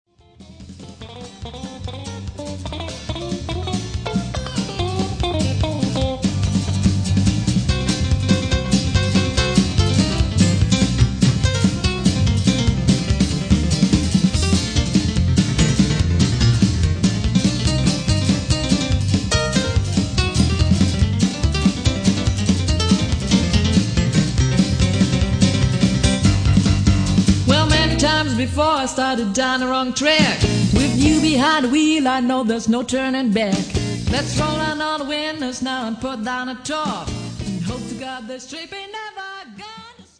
PIANO
Country & Western  :